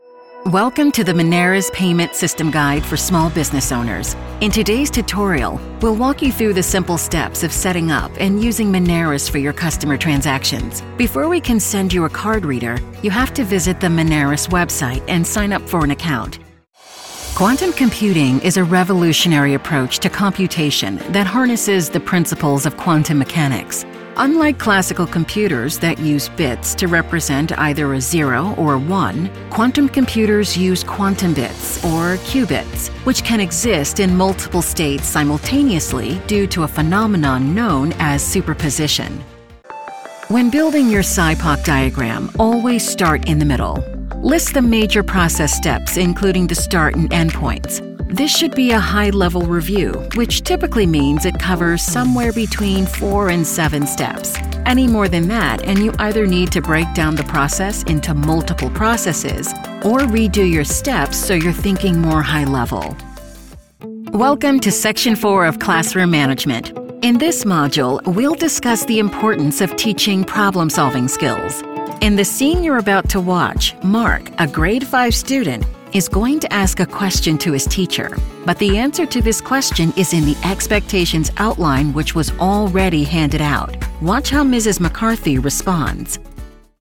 Distinctive, Mature, Amicale, Chaude, Corporative
E-learning